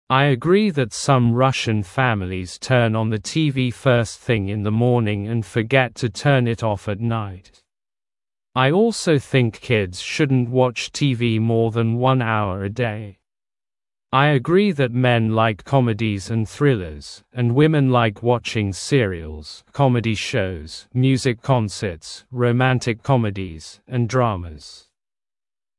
Произношение:
[Ай э’грии зэт сам рашн фэмализ тён он зэ ти ви фёст зинг ин зэ моонинг энд фэ’гэт ту тён ит оф эт найт. ай олсоу зинг кидз шуднт вотч ти ви моа зэн ван ‘ауа э дей.
Ай э’грии зэт мэн лайк ‘комэд из энд ‘зрилэз, энд ‘вимэн лайк ‘вочинг ‘сиэриэлз, ‘комэди шоу, ‘мьюзик ‘консэтс, ро’мэнтик ‘комэд из энд ‘драамаз].